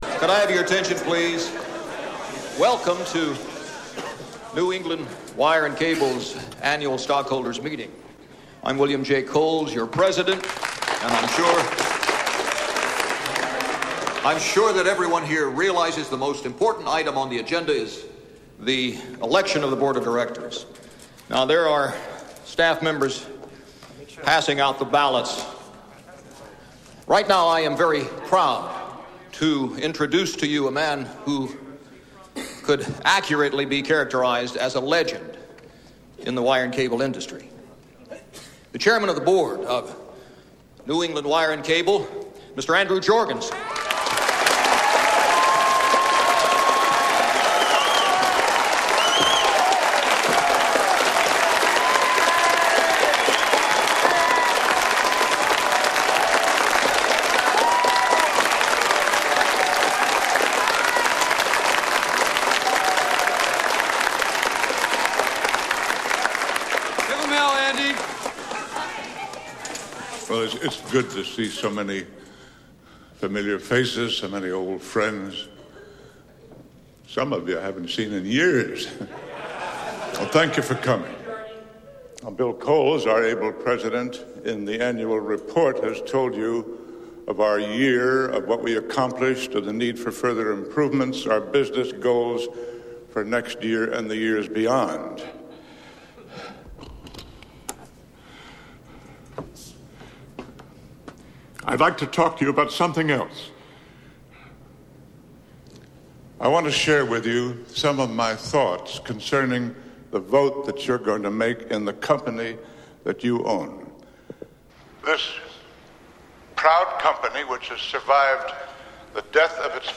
Andrew Jorgenson Addresses the Stockholders